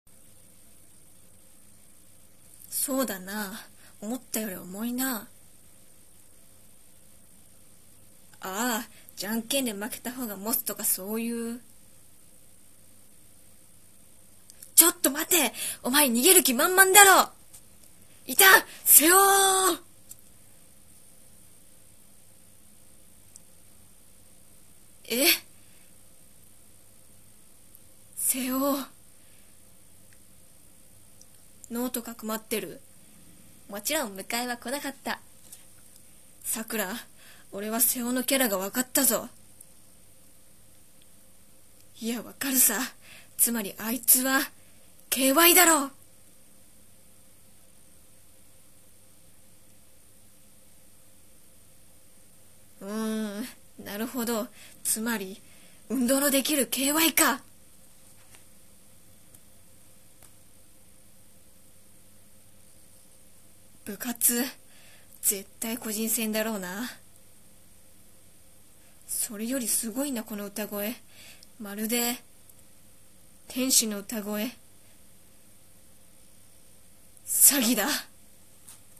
【アフレココラボ募集】